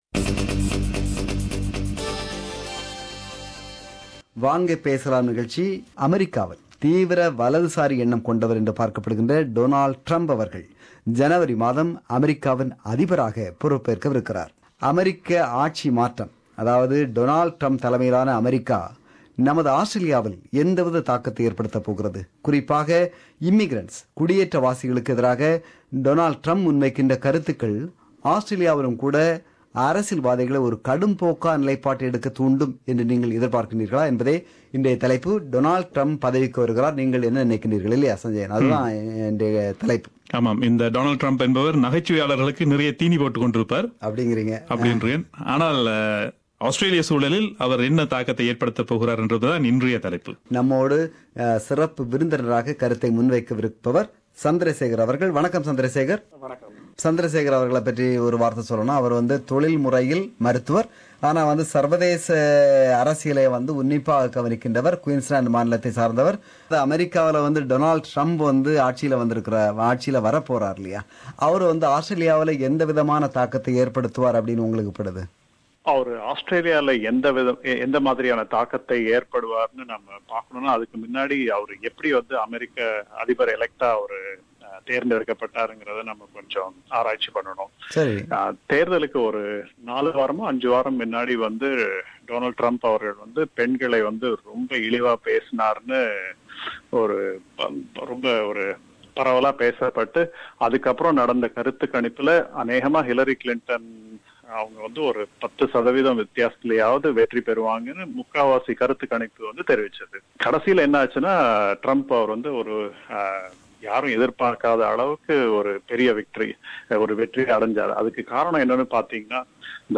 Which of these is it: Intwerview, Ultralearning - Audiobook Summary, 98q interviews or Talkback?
Talkback